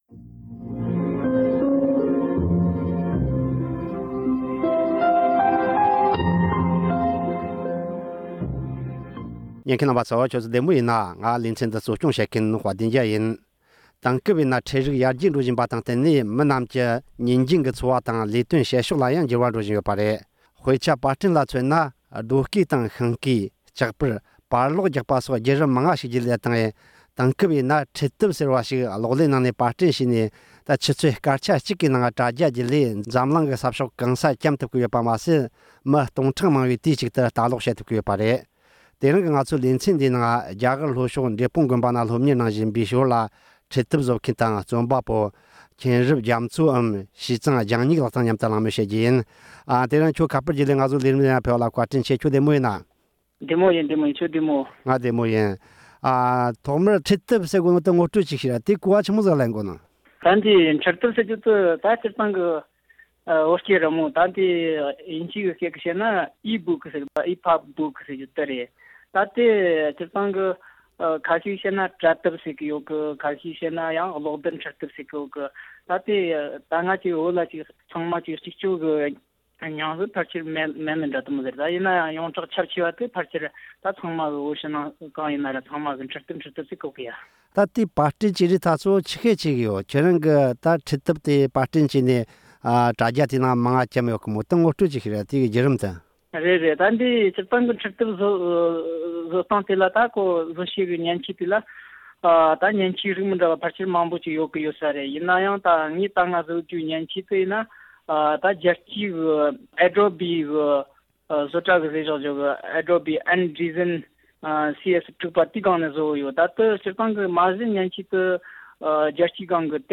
གླེང་མོལ་བྱས་བར་གསན་རོགས་གནང་།།